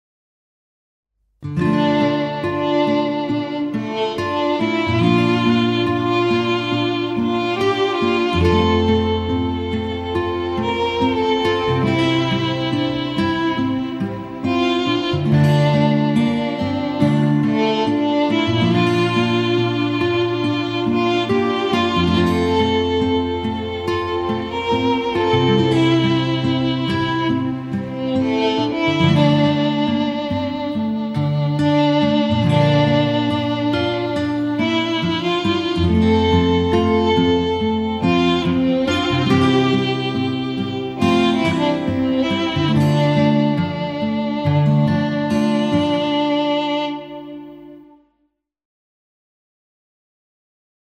intimiste - folk - melodieux - romantique - aerien